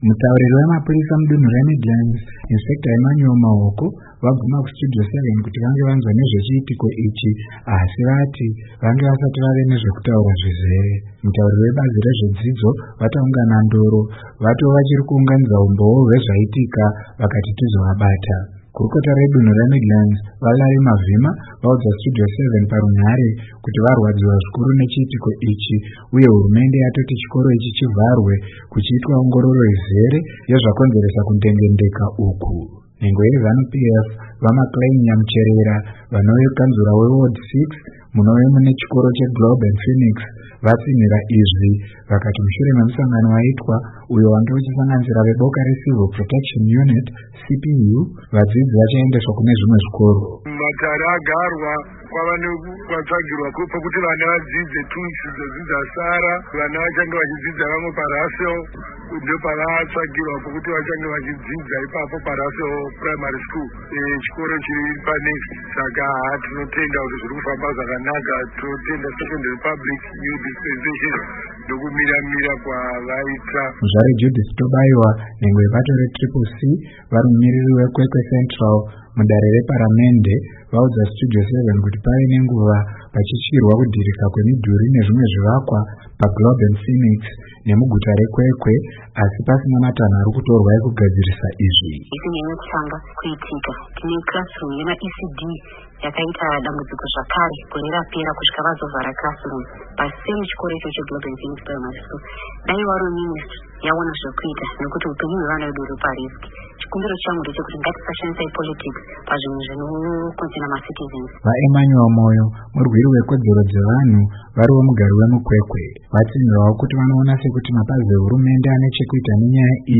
Gurukota redunhu reMidlands VaLarry Mavhima vaudza Studio 7 parunhare kuti varwadziwa zvikuru nechiitiko ichi uye hurumende yatoti chikoro ichi chivharwe kuchiitwa ongororo izere yezvakonzeresa kundengendeka uku.